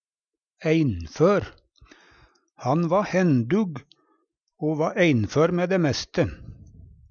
einfør - Numedalsmål (en-US)